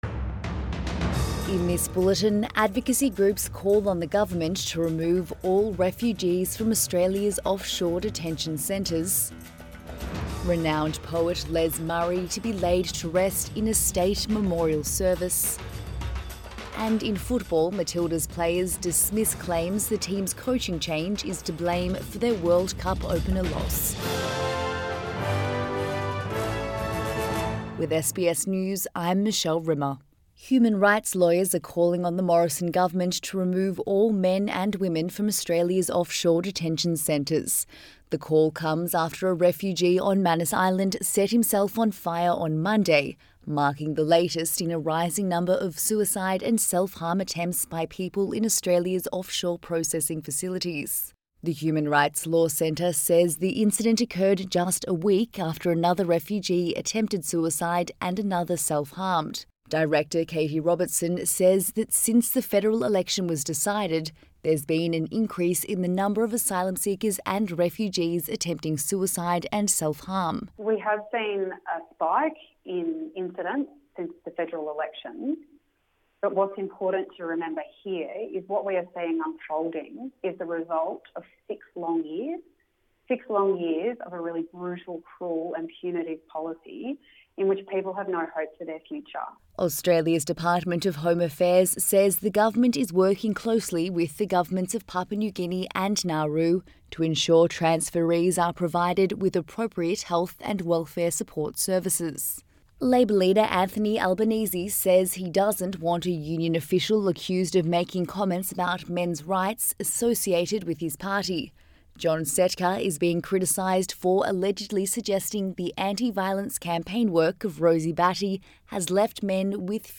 AM bulletin 12 June 2019